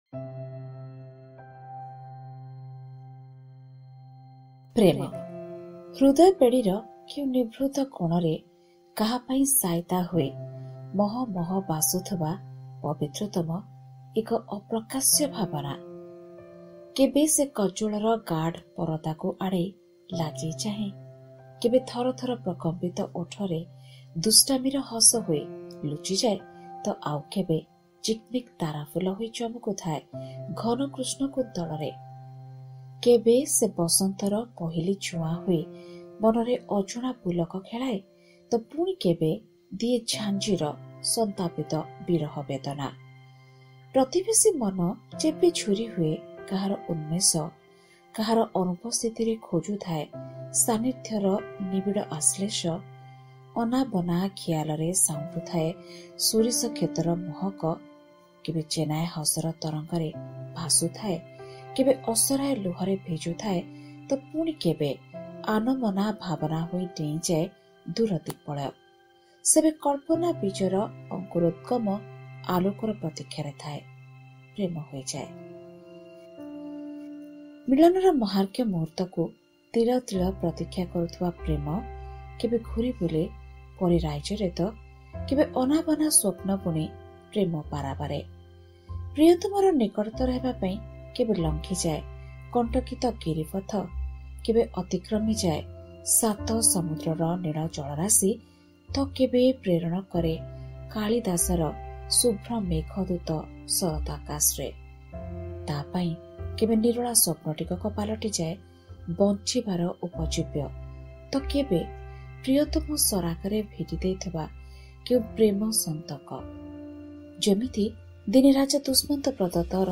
Odia Stories